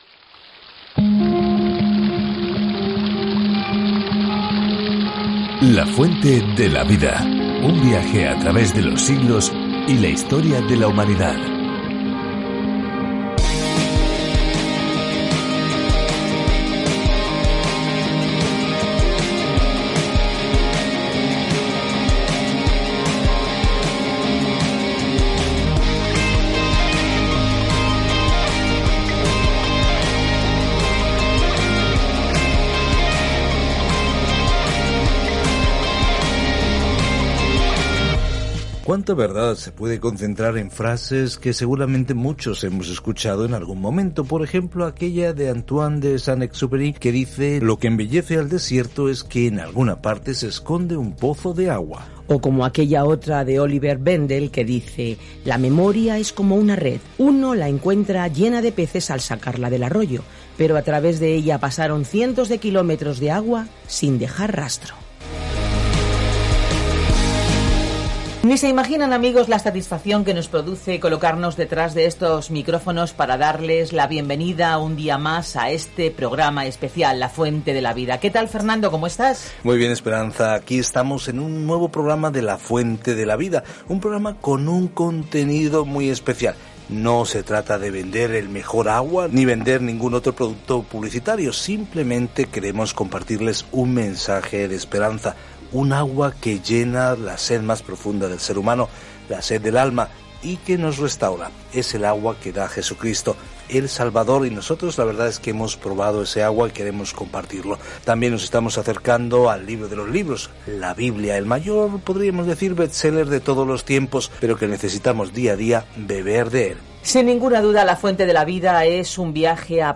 Escritura PROVERBIOS 11:1-25 Día 13 Iniciar plan Día 15 Acerca de este Plan Los proverbios son oraciones cortas extraídas de largas experiencias que enseñan la verdad de una manera fácil de recordar: verdades que nos ayudan a tomar decisiones sabias. Viaja diariamente a través de Proverbios mientras escuchas el estudio en audio y lees versículos seleccionados de la palabra de Dios.